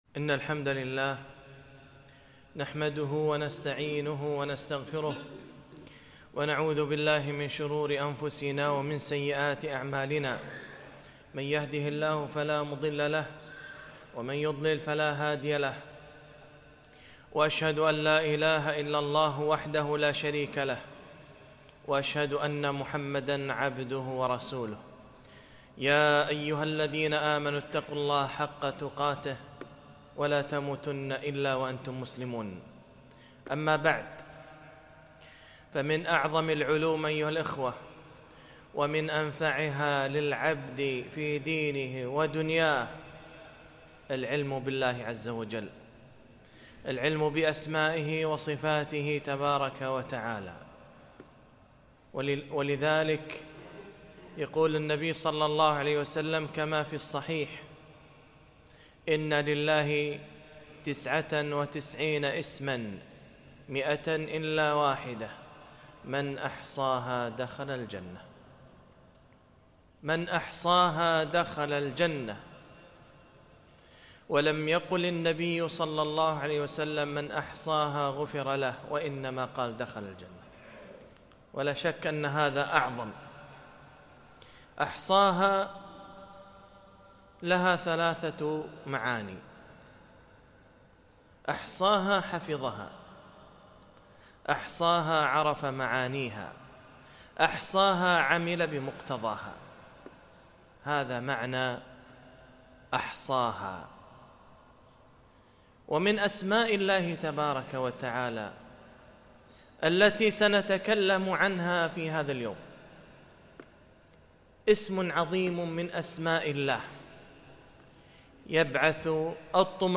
خطبة - اسم الله الرزاق